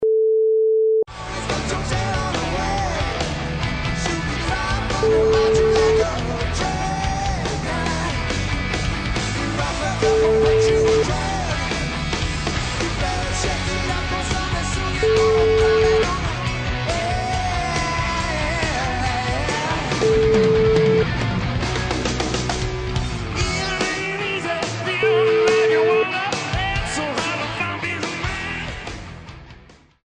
Live In West Palm Beach, FL